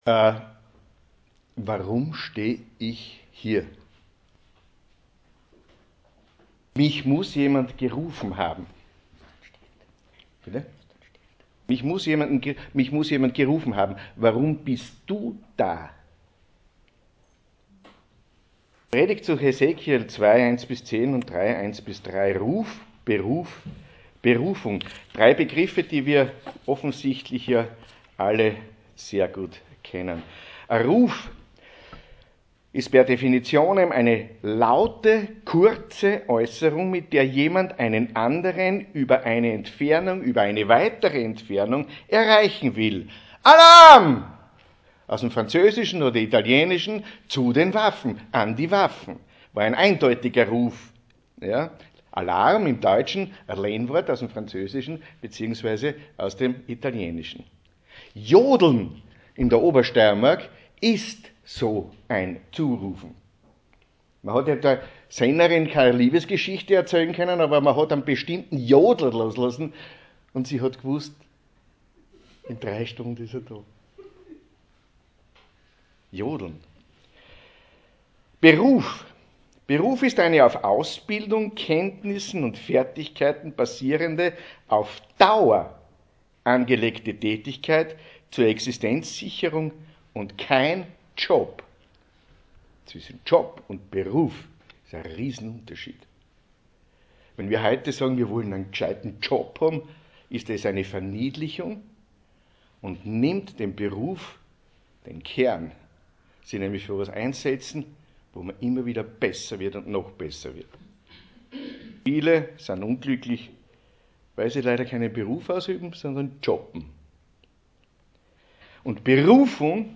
GOTTES-DIENST IM GEMEINDESAAL GNIEBING (Nr.148) - EFG Feldbach